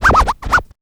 Scratch 211.wav